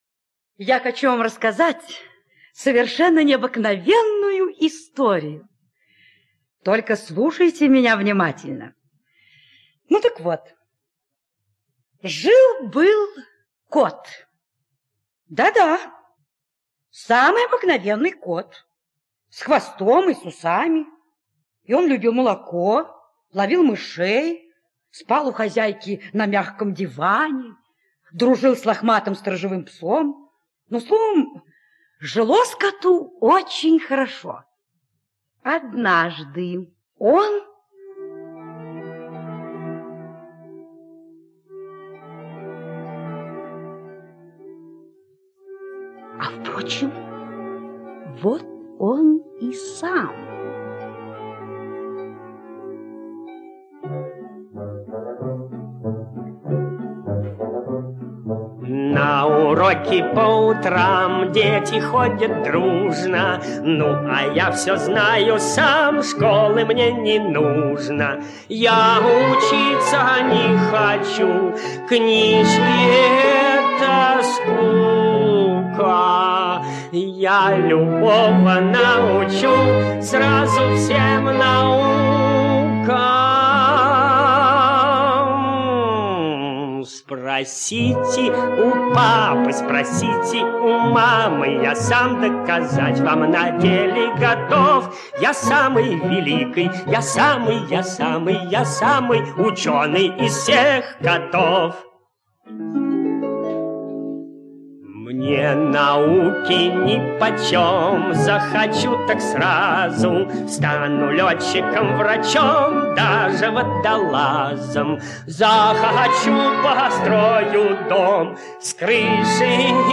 Кот-хвастун - аудиосказка Левшина - слушать онлайн